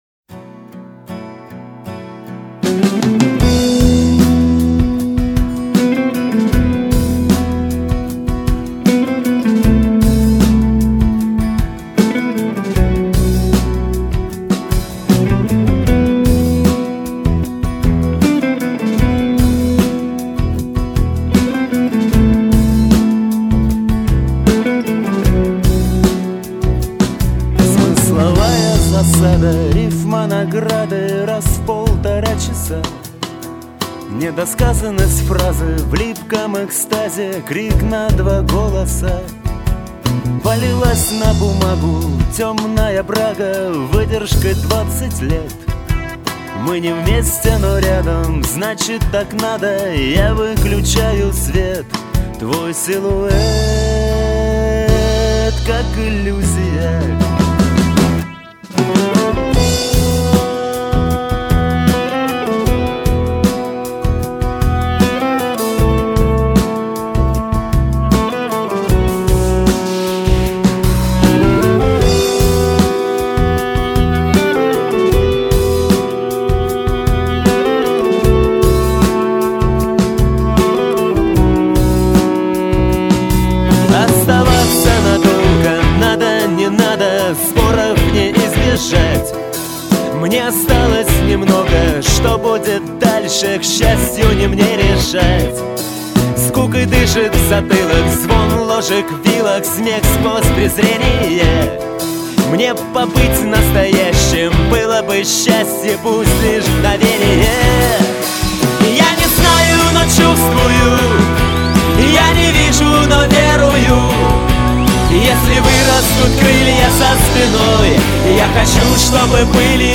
Жанр: General Mainstream Rock